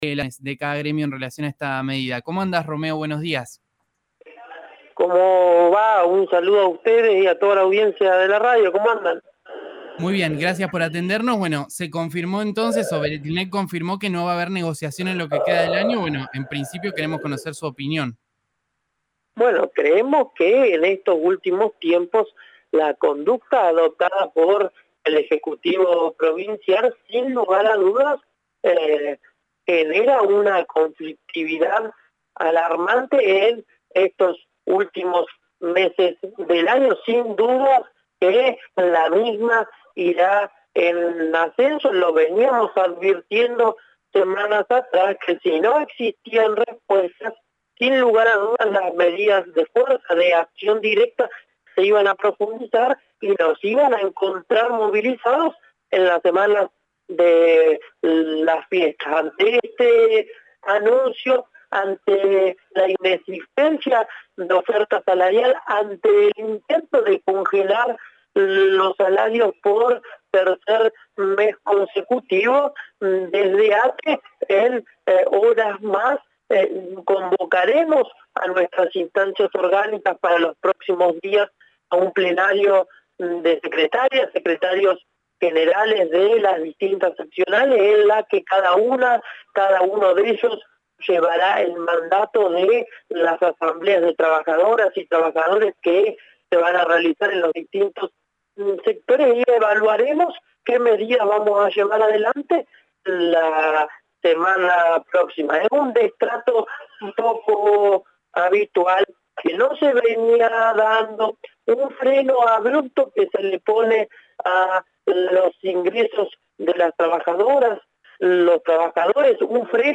dialogó con RÍO NEGRO RADIO y reveló que esta semana habrá un plenario de secretario generales donde se discutirá el plan de acción a ejecutar.